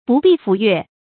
不避斧鉞 注音： ㄅㄨˋ ㄅㄧˋ ㄈㄨˇ ㄩㄝˋ 讀音讀法： 意思解釋： 斧鉞：古代的兵器。